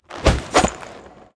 rogue_skill_dash_attack_end.wav